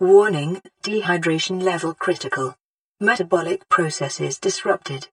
WaterVeryLow.wav